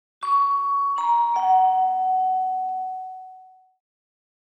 Doorbell